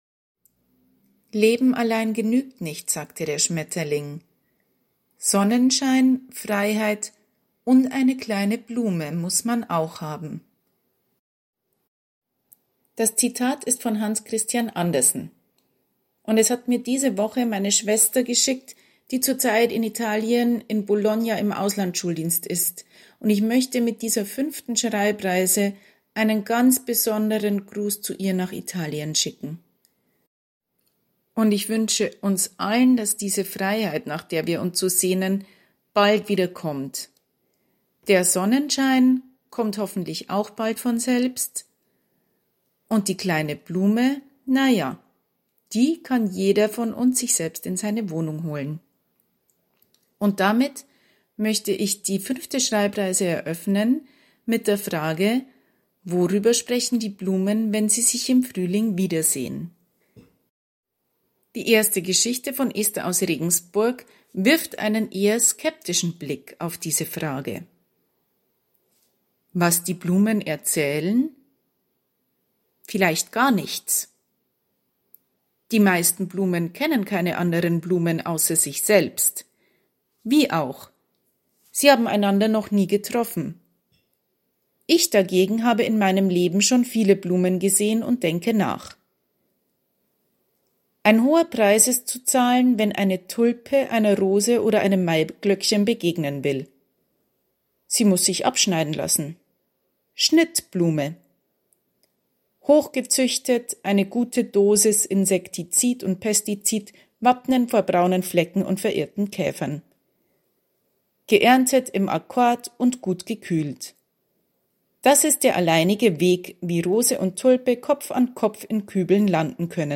vorgelesen